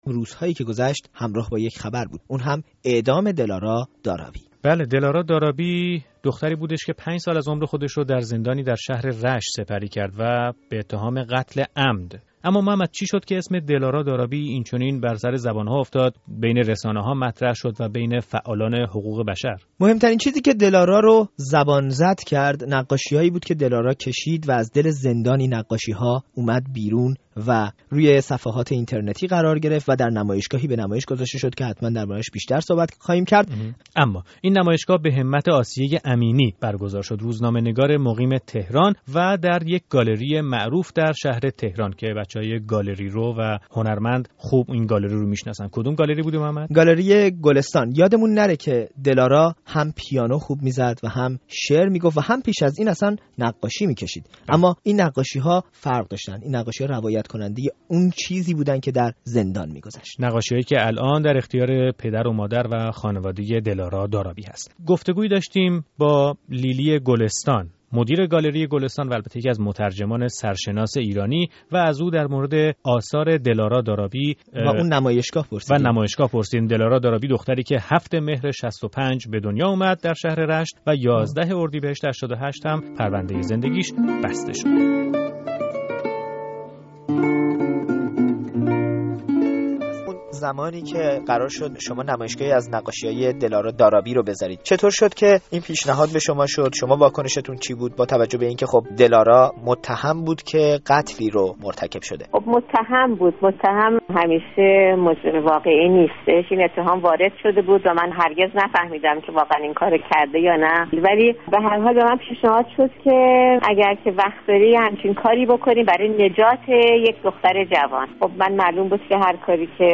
گفت و گو با لیلی گلستان درباره نقاشی های دل آرا دارابی